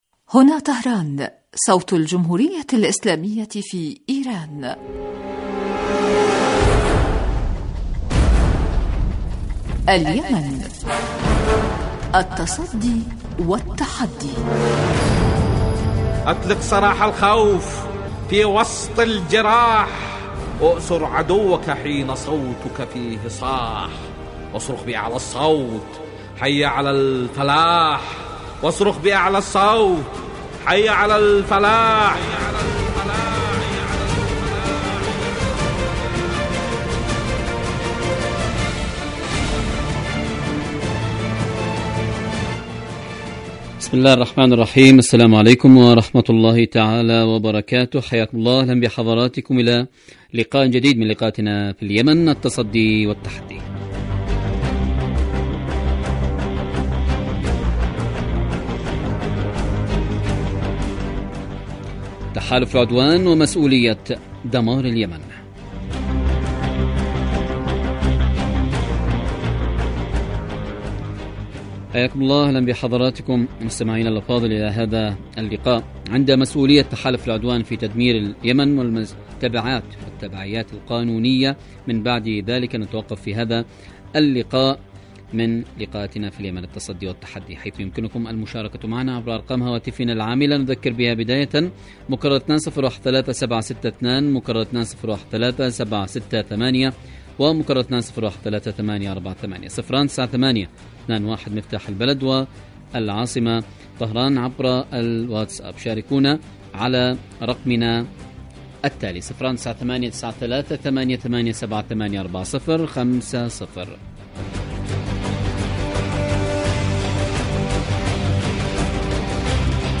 برنامج سياسي حواري يأتيكم مساء كل يوم من إذاعة طهران صوت الجمهورية الإسلامية في ايران .
البرنامج يتناول بالدراسة والتحليل آخر مستجدات العدوان السعودي الأمريكي على الشعب اليمني بحضور محللين و باحثين في الاستوديو.